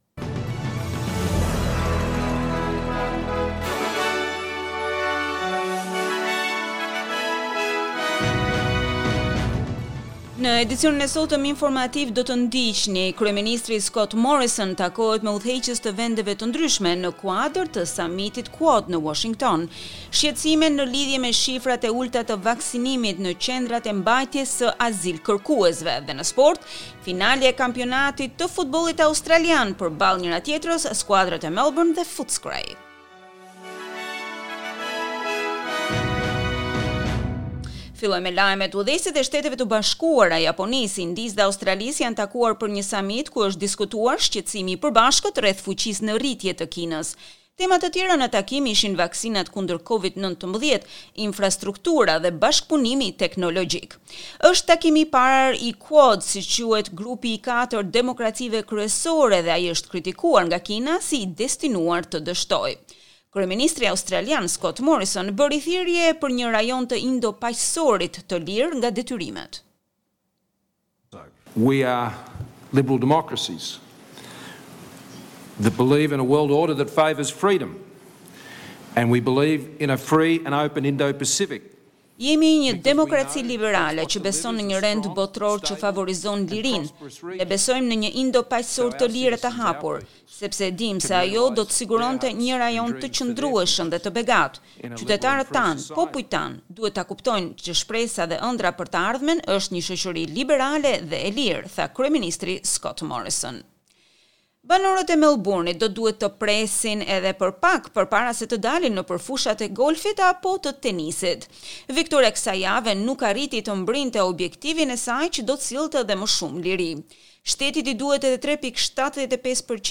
SBS News Bulletin in Albanian- 25 September 2021